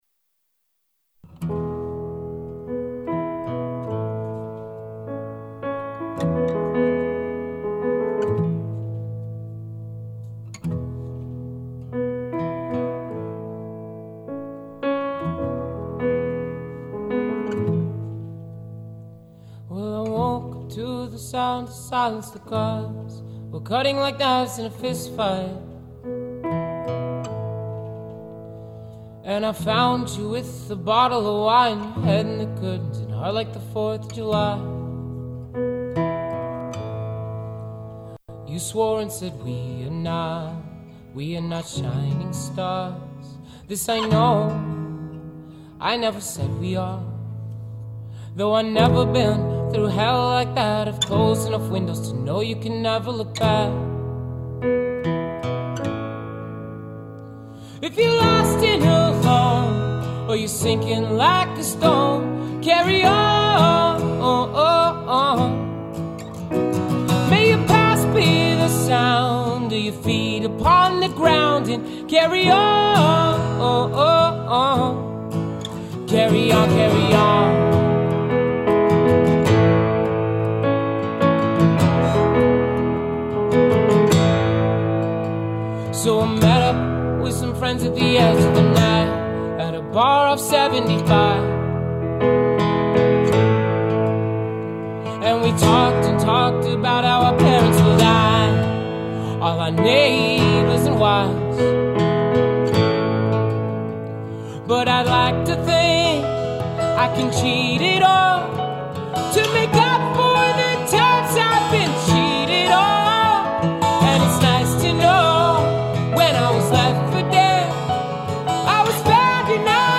They have an acoustic version floating around the intertron.
Warning, its just so emotional!